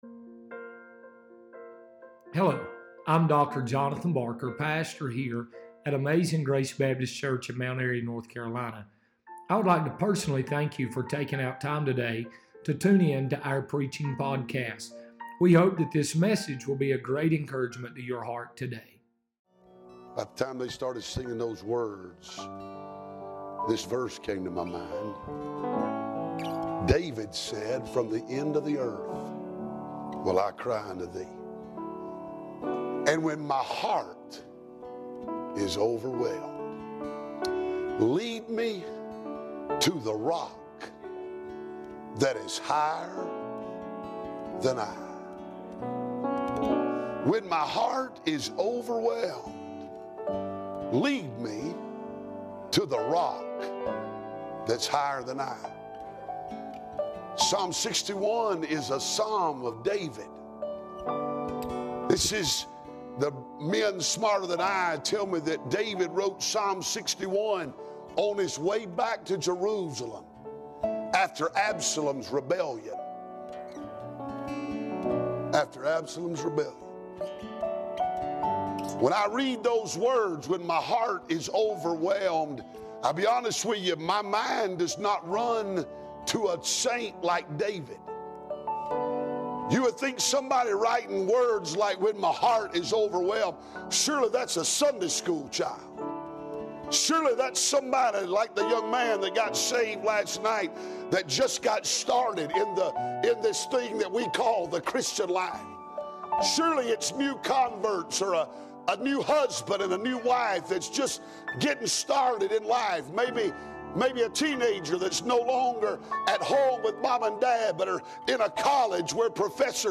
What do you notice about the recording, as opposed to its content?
Spring Revival